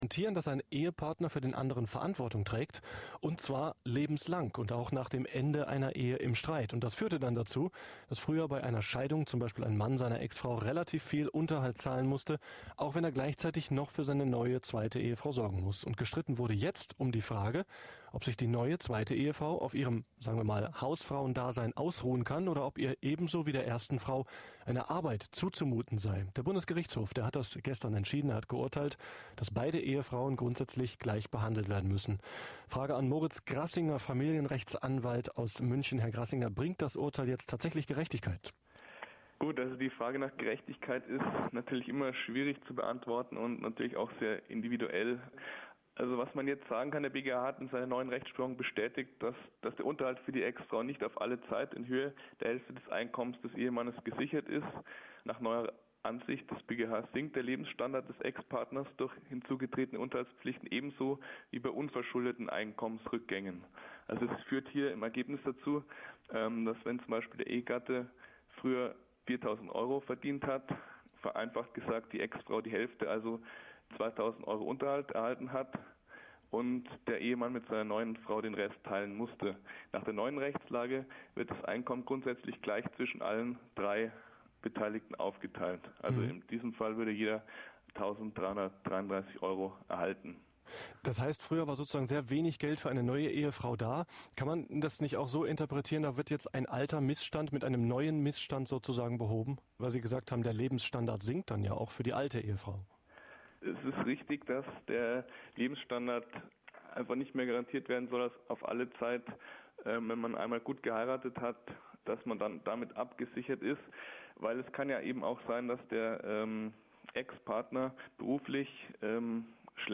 im MDR-Info Radio-Interview zum aktuellen BGH Urteil